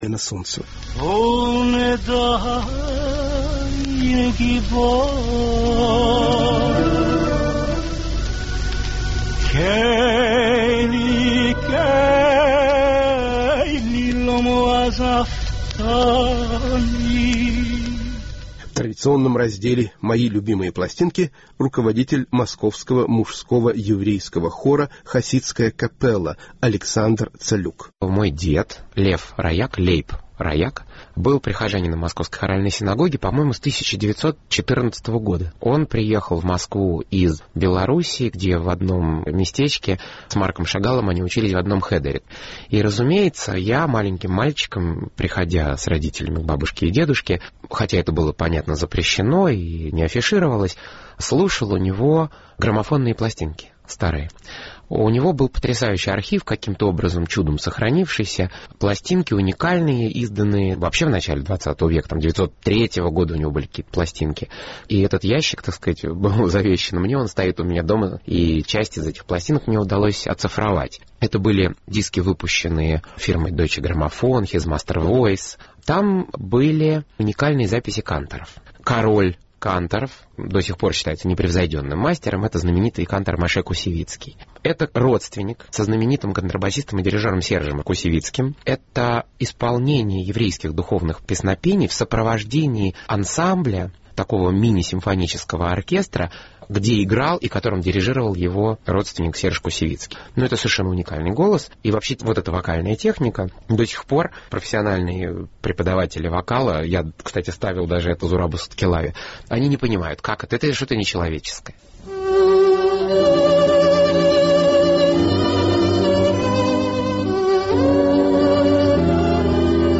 Московский мужской хор "Хасидская капелла"